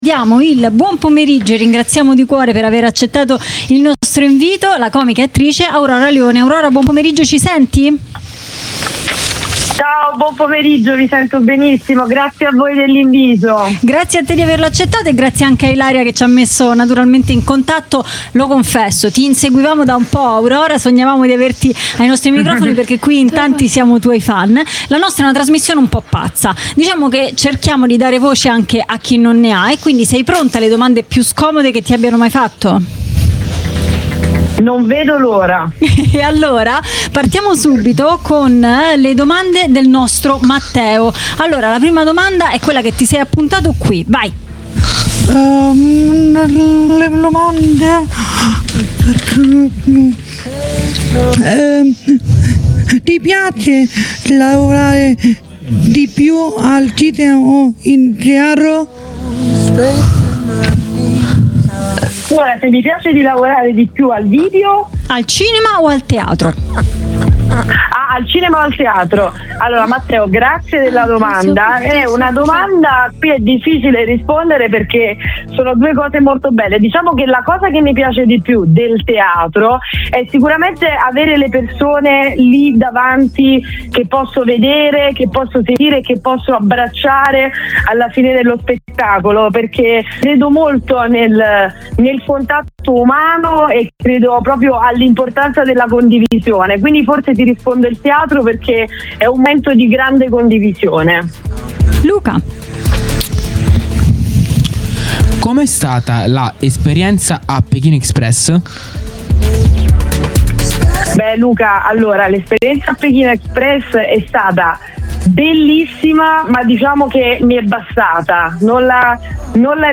Jolly Roger - S2xE15 - Intervista ad Aurora Leone